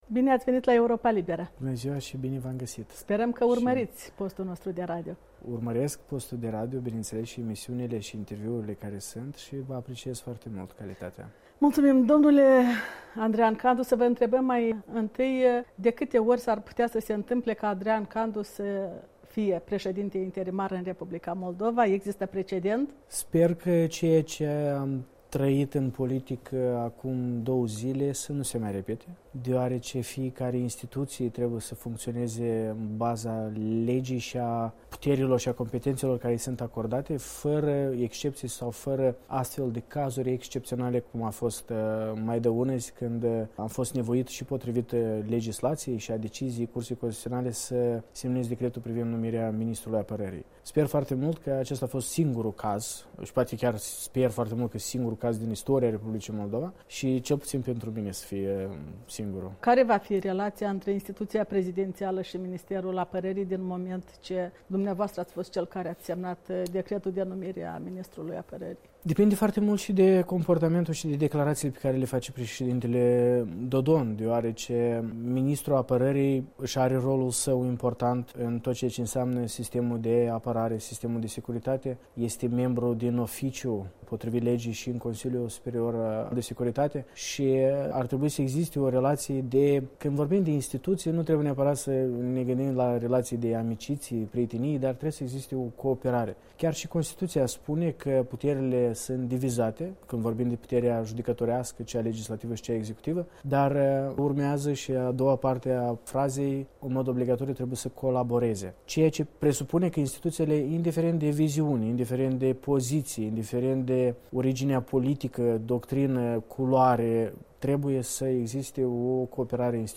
De vorbă cu președintele Parlamentului, despre cât de des l-ar putea înlocui pe președintele țării, de pildă la numirea miniștrilor.
Andrian Candu răspunde întrebărilor Europei Libere